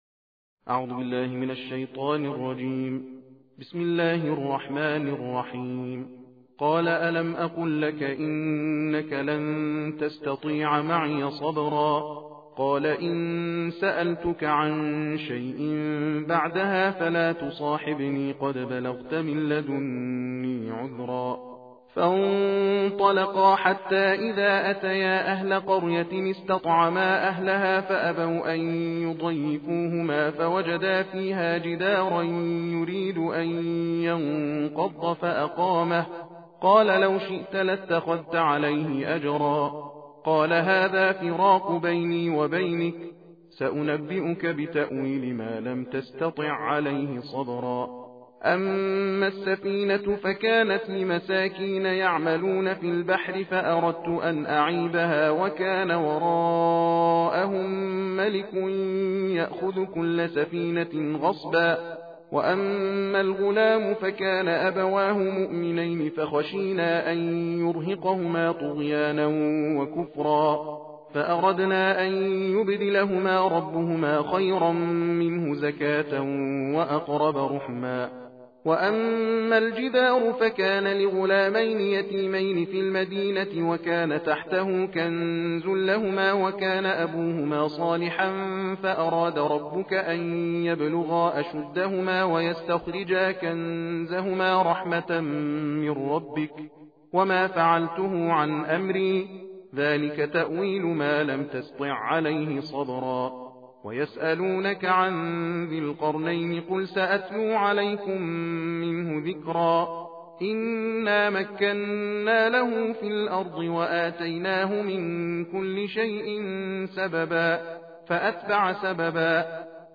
تلاوت جزء شانزدهم قرآن‌کریم +متن و ترجمه
ترتیل جزء شانزدهم قرآن کریم را در ادامه به همراه متن و ترجمه می توانید دریافت نمایید.